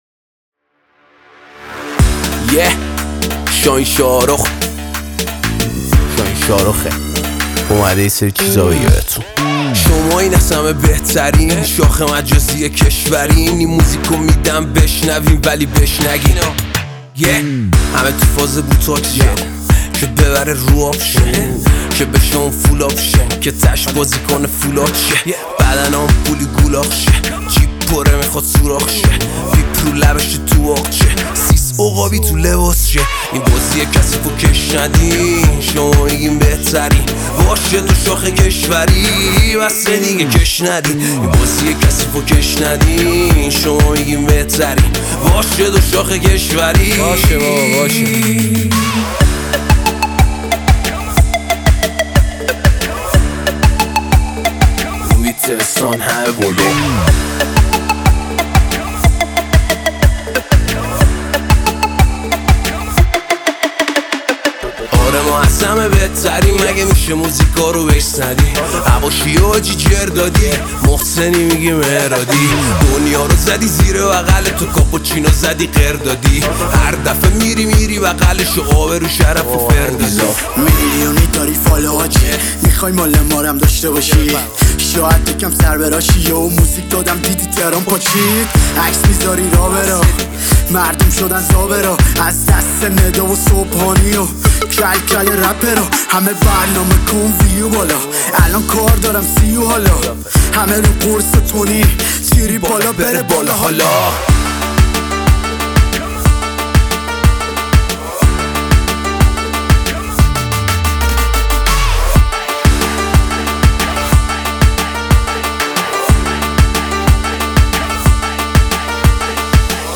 ریمیکس